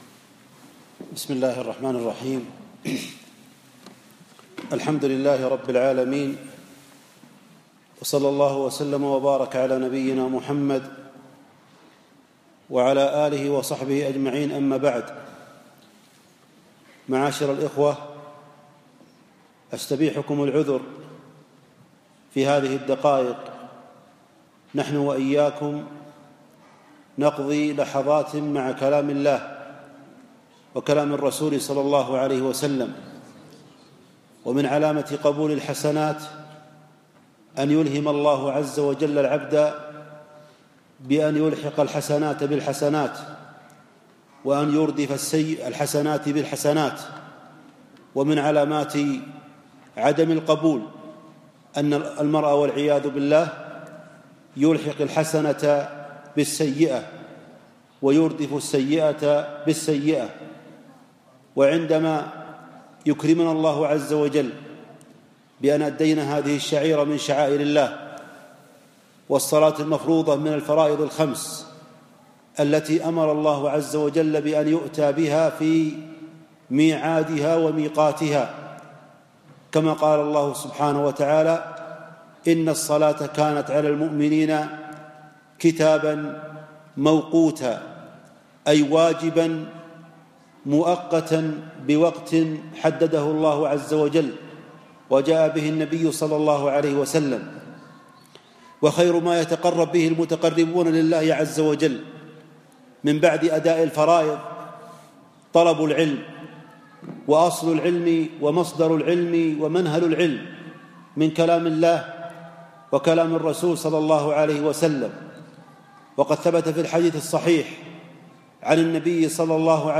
أقيمت المحاضرة في ادولة الكويت